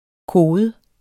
Udtale [ ˈkoːðə ]